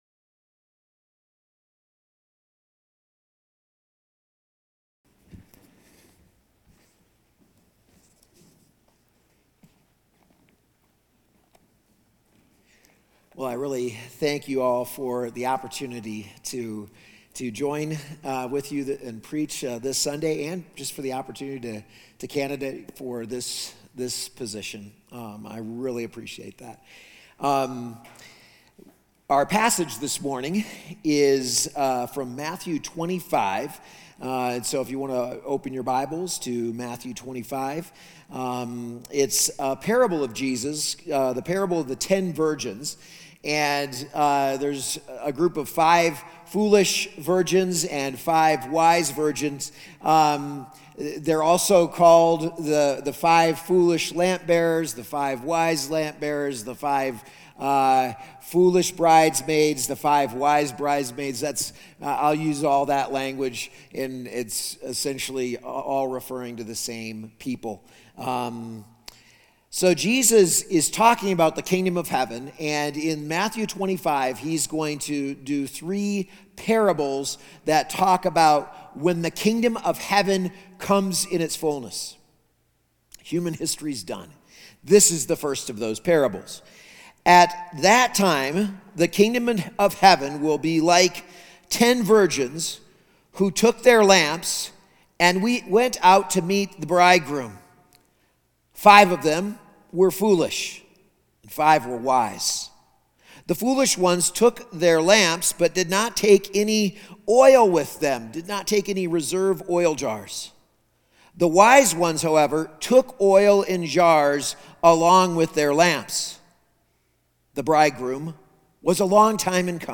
A message from the series "We are the Church."